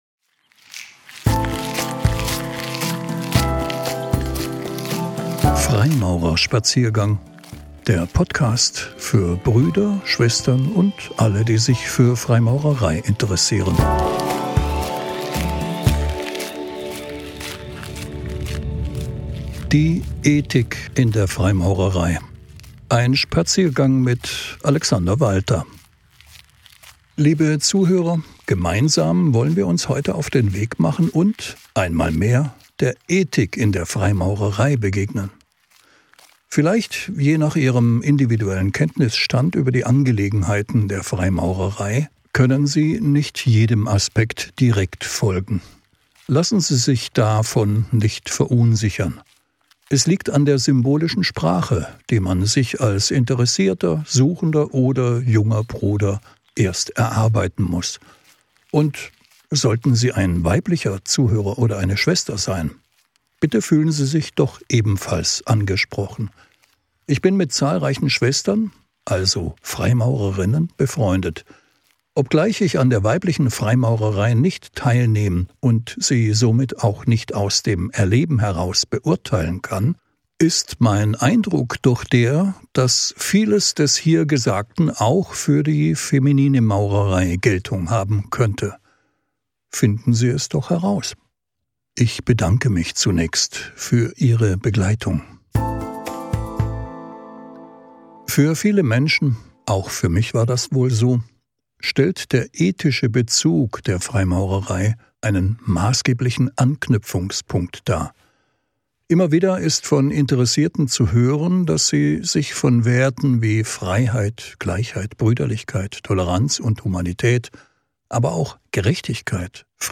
Ein Spaziergang